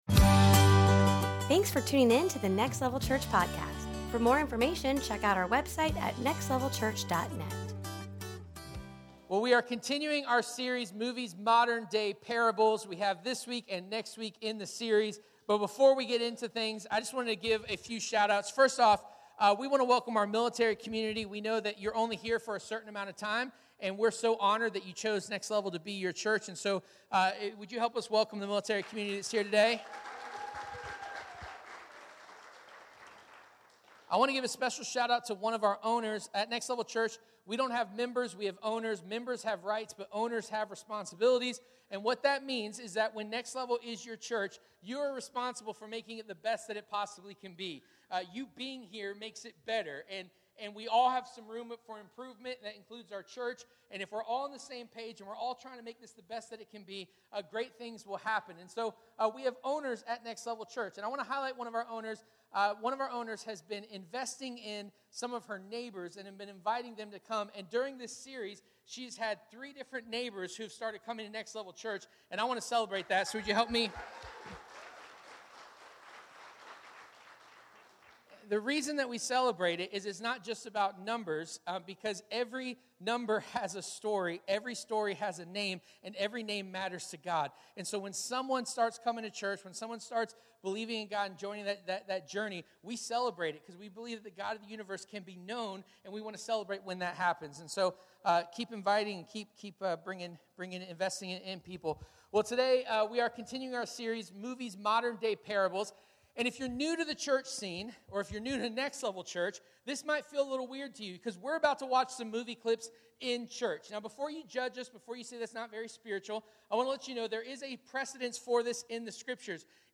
Modern Day Parables 2023 Service Type: Sunday Morning « Movies Modern Day Parables